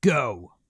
Voices / Male / Go 1.wav
Go 1.wav